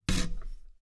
Metal_100.wav